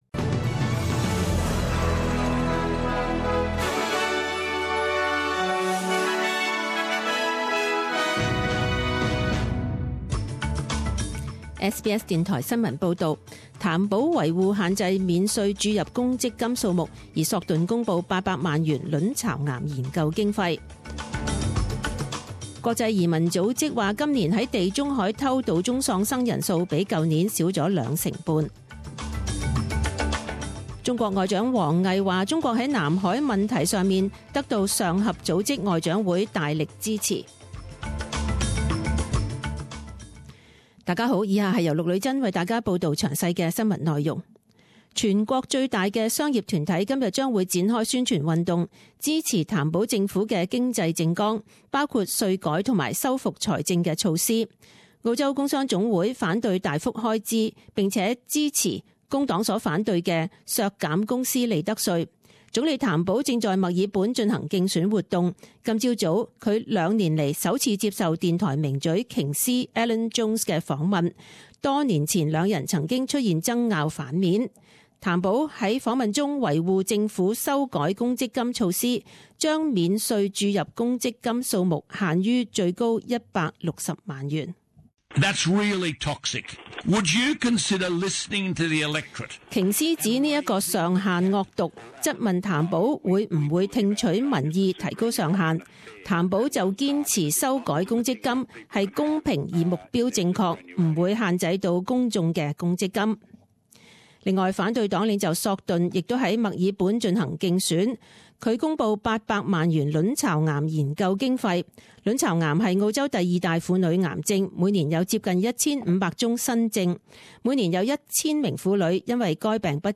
10am News Bulletin 25.05.2016
Details News Bulletins